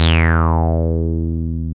77.09 BASS.wav